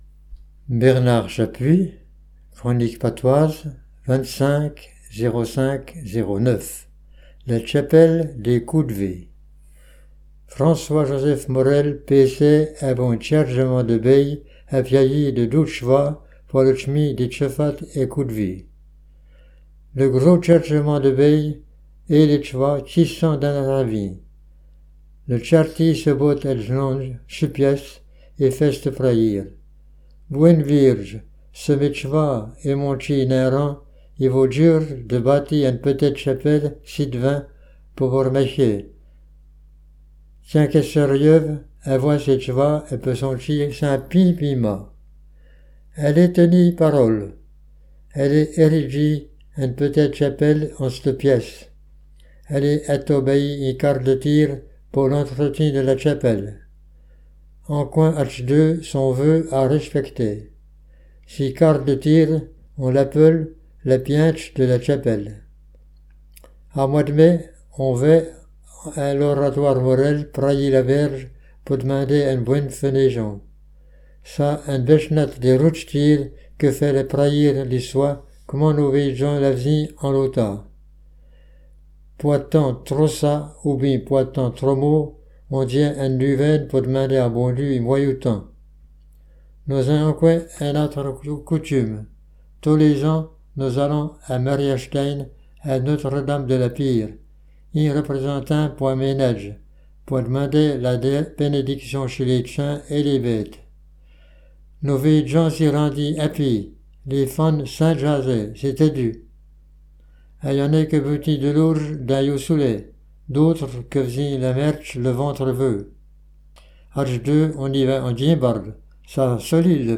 Patois Jurassien